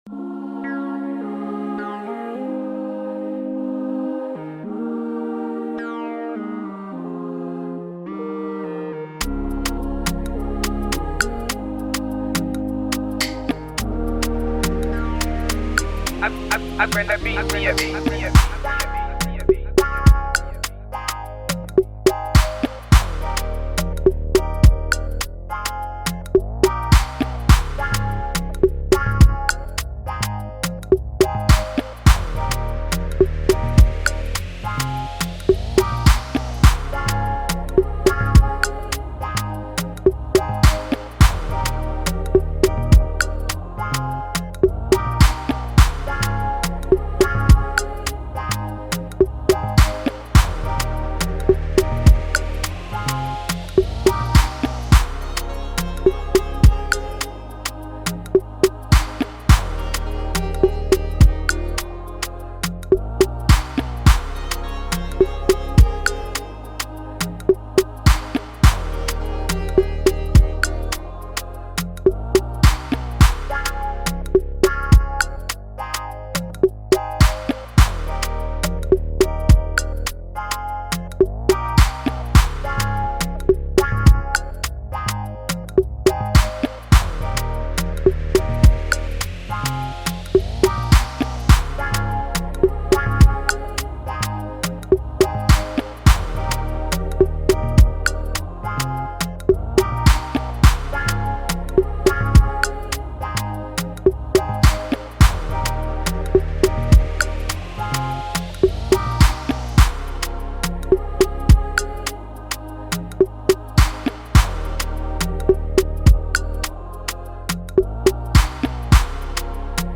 Ghana MusicInstrumentalsMusic
Off the Ep lies this free Afrobeat instrumentals.